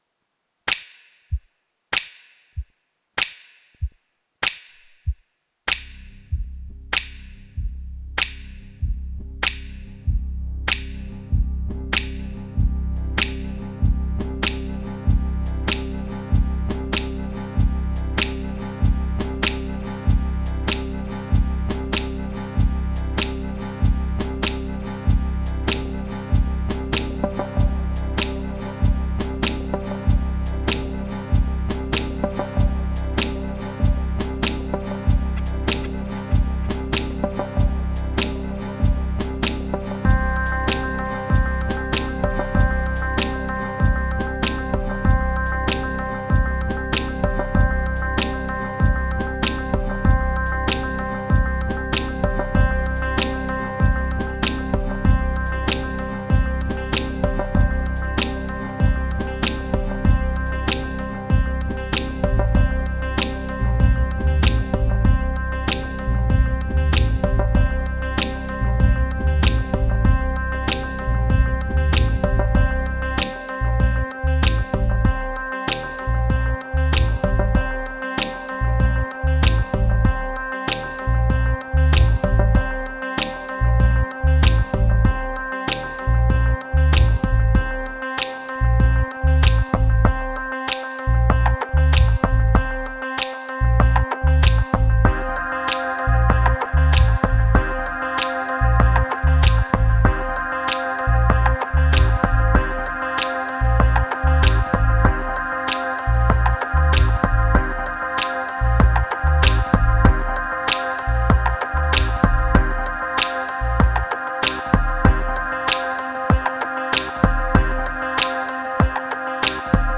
Dub Moods Hope Means Attraction Long Attic Enlightenment